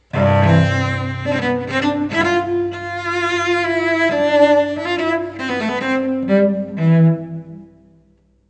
cello.wav